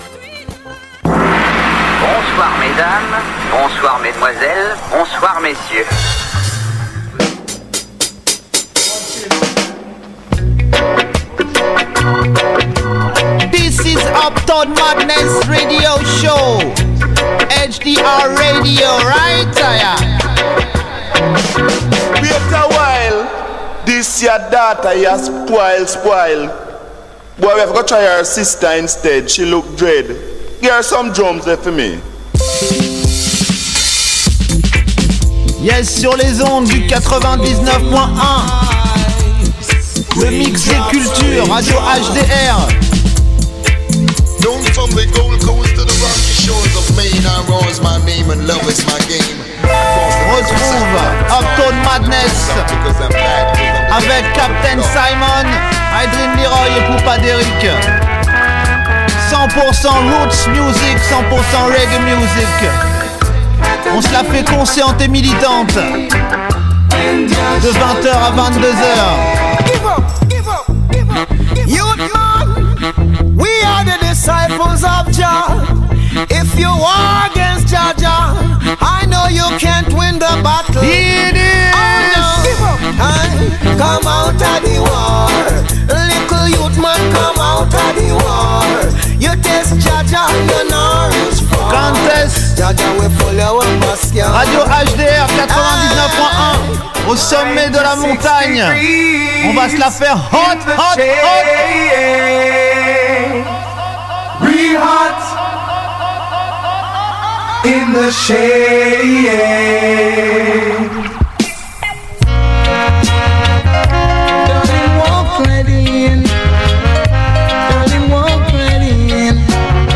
grosses sélections Roots et Dub inna Sound System Style